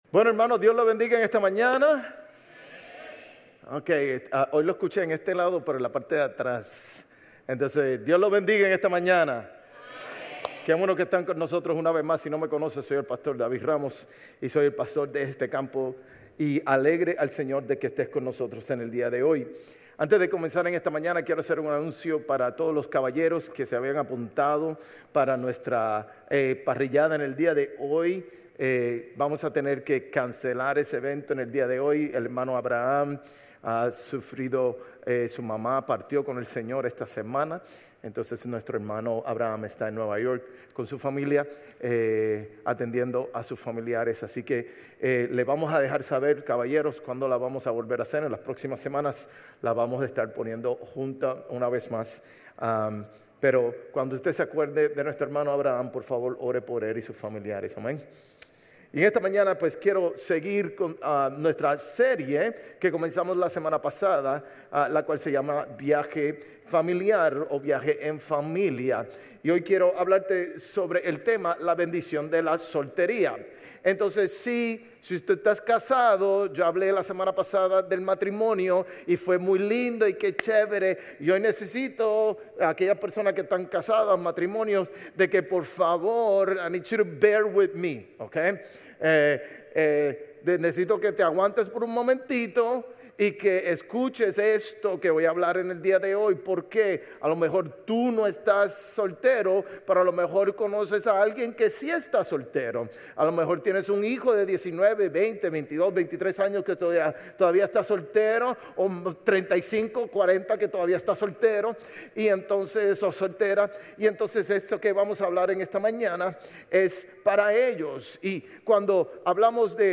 Sermones Grace Español 8_17 Grace Espanol Campus Aug 17 2025 | 00:43:05 1x 00:00 / 00:43:05 Subscribe Share RSS Feed Share Link Embed